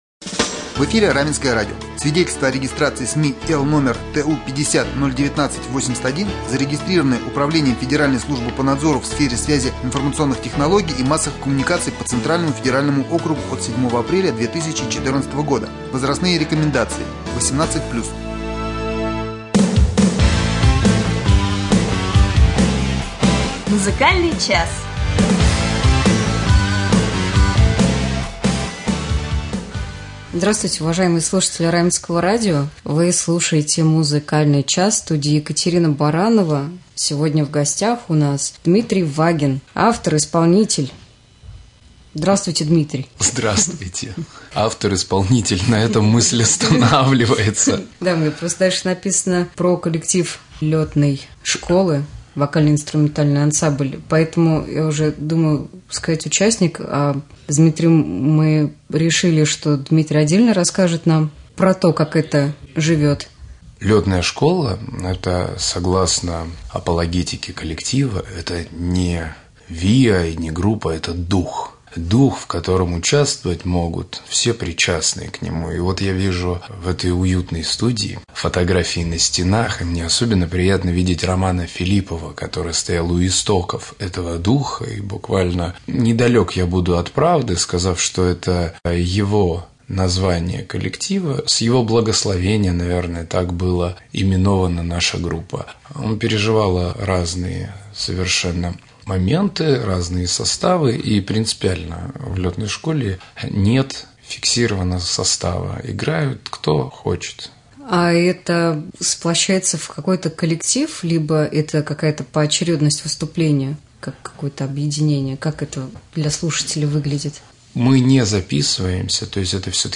интервью
с автором-исполнителем